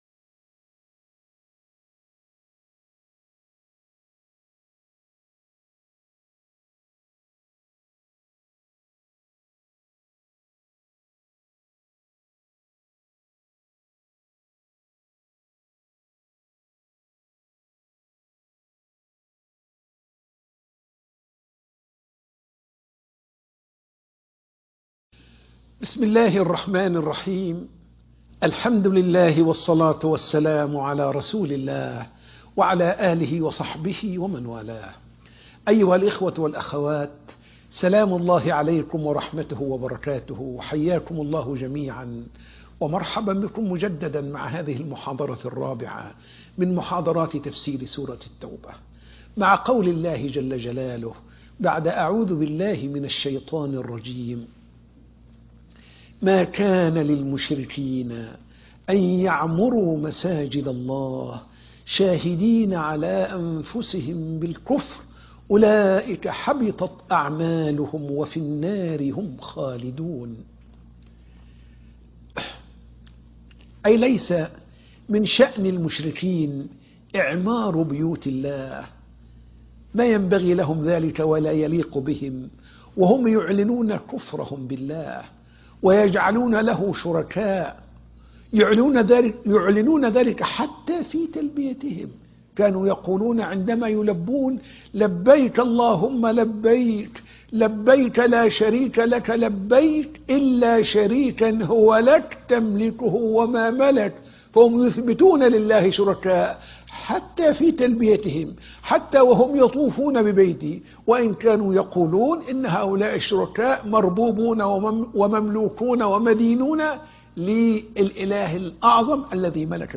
تفسير سورة التوبة 17 - المحاضرة 4